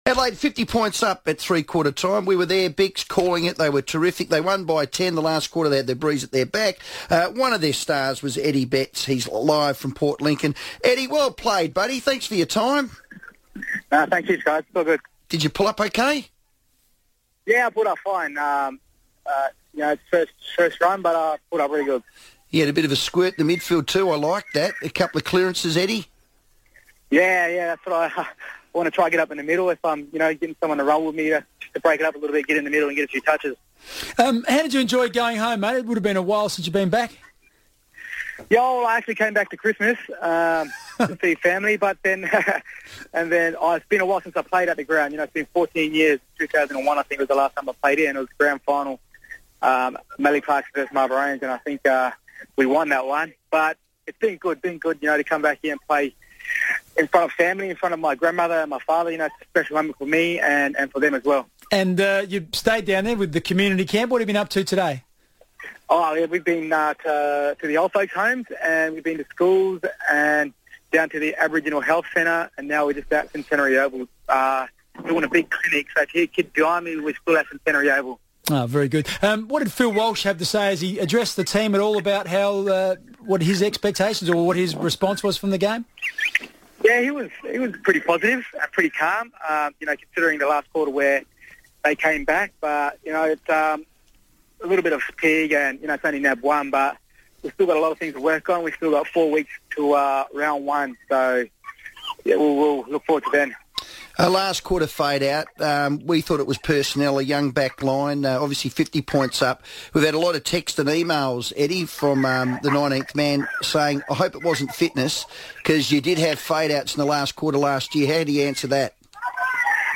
Eddie Betts spoke on radio after booting four goals on his own stomping ground in Port Lincoln